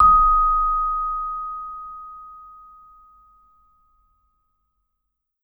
Index of /90_sSampleCDs/Sampleheads - Dave Samuels Marimba & Vibes/VIBE CMB 1B